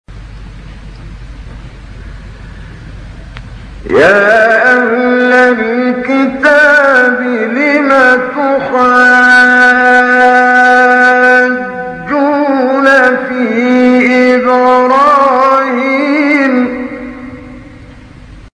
گروه شبکه اجتماعی: فرازهای صوتی از کامل یوسف البهتیمی که در مقام بیات اجرا شده است، می‌شنوید.